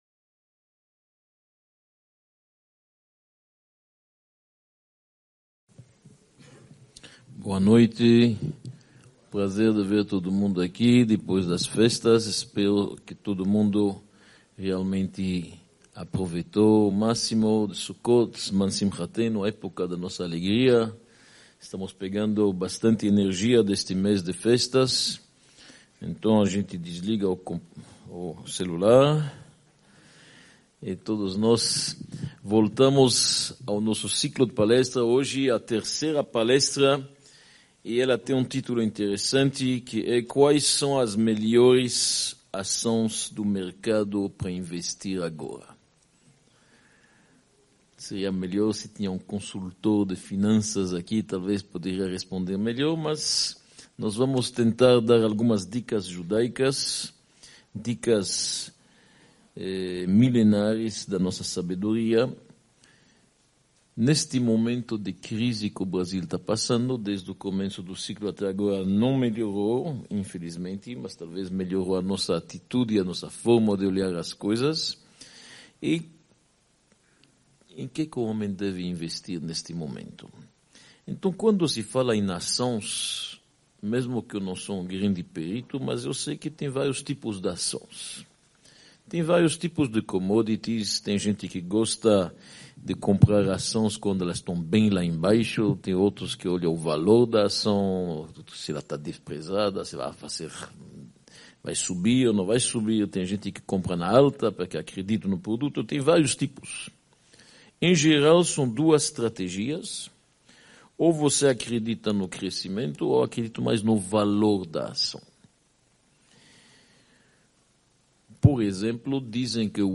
Palestra-Quais-as-melhores-ações-do-mercado-para-investir-agora.mp3